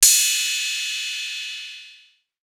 Crashes & Cymbals
Crash [Rich].wav